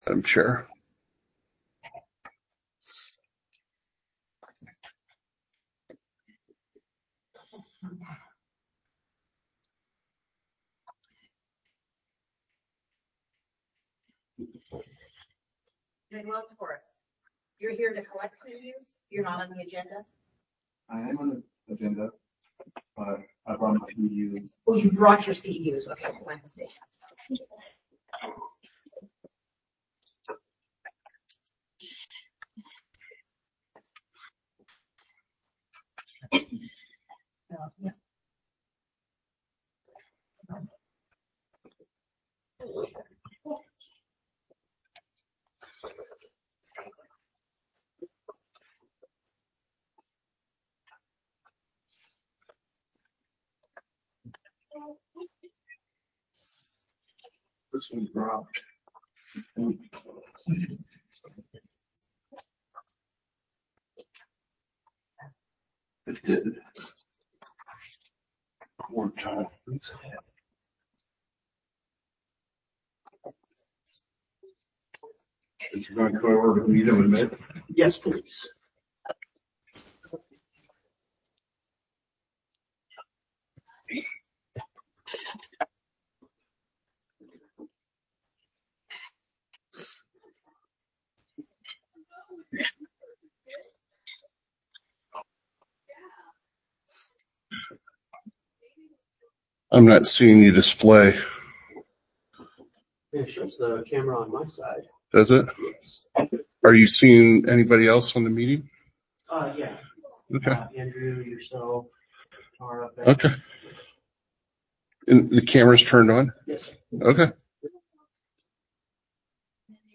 Respiratory-Board-Meeting-2025_02_20.mp3